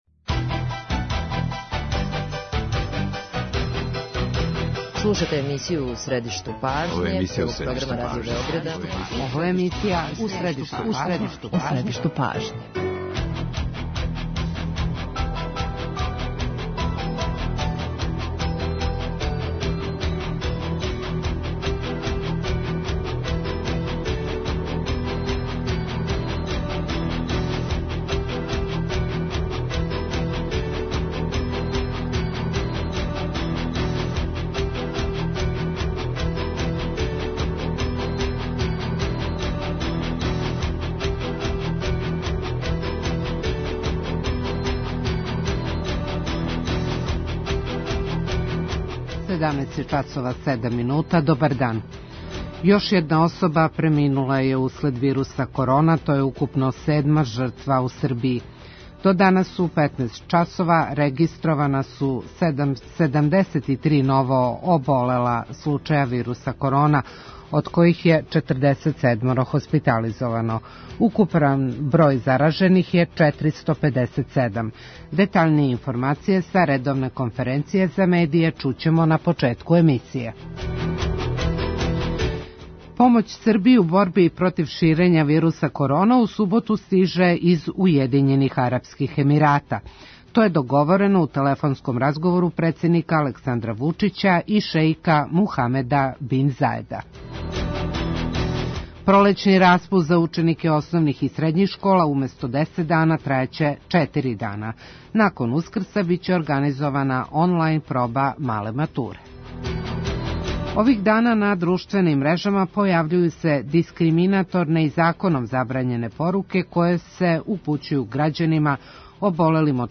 Како би требало да се понашају послодавци за време ванредног стања? Саговорница емисије је Бранкица Јанковић, повереница за заштиту равноправности.